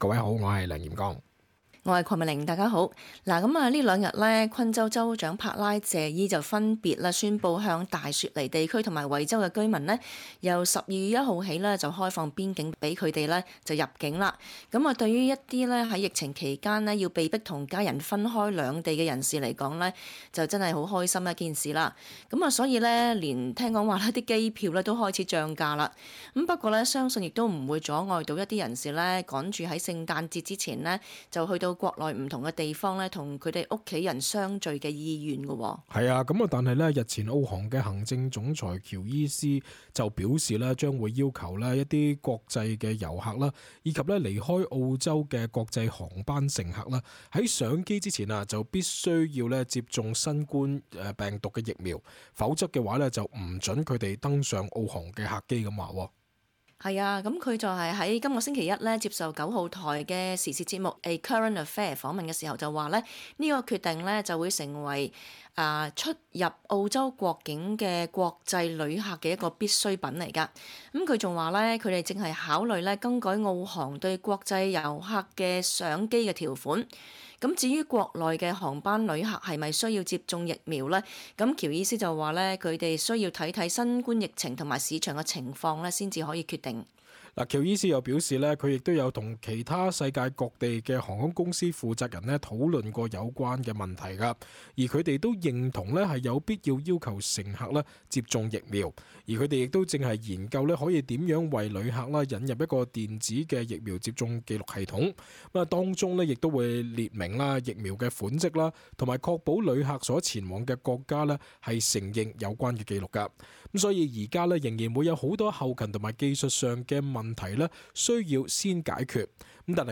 cantonese_talkback_upload_-_nov_26.mp3